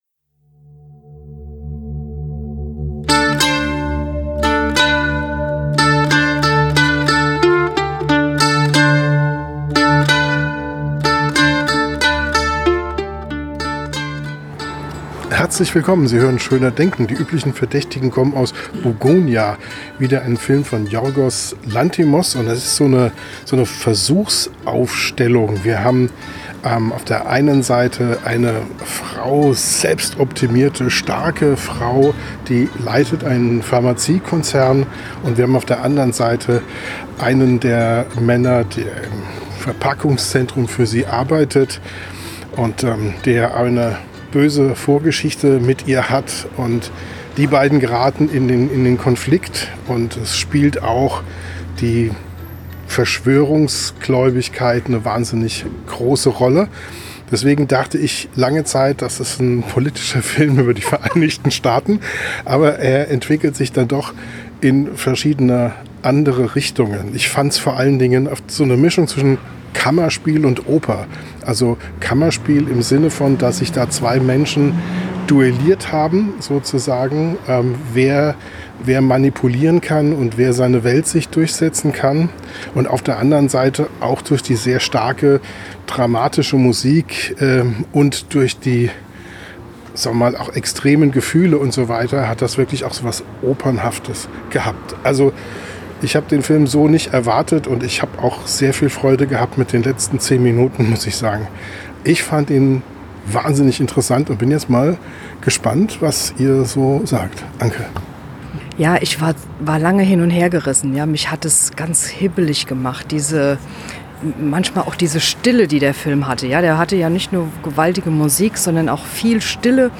Dementsprechend haben wir ganz unterschiedliche Meinungen im Podcast direkt nach dem Kino.
Gespoilert wird erst spät – und nach der akustischen Spoilerwarnung.